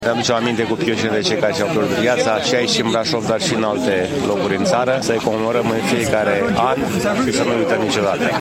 Aproximativ 200 de oameni au participat la momentul solemn care a avut loc la Cimitirul Eroilor din centrul Brașovului.
Primarul, George Scripcaru: